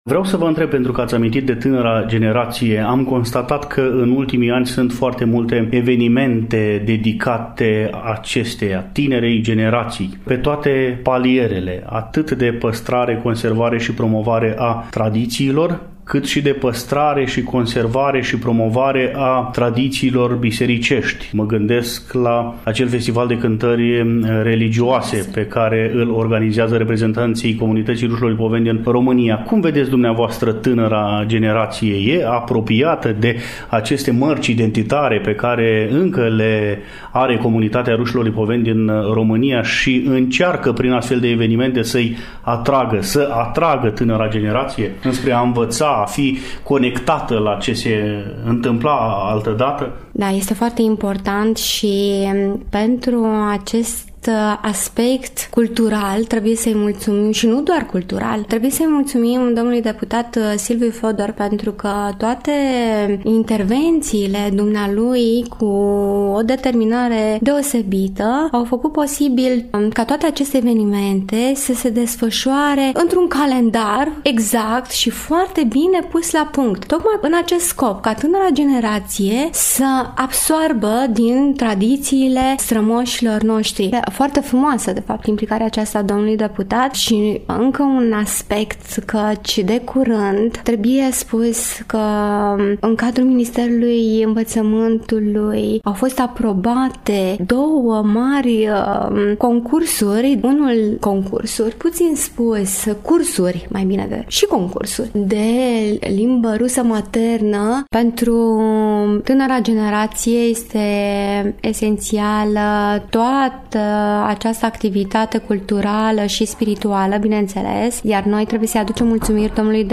Împreună cu invitata noastră, tragem concluziile dialogului pe care l-am purtat astăazi, interviu presărat de dragostea rușilor staroveri pentru sfânta biserică și pentru credința străbună.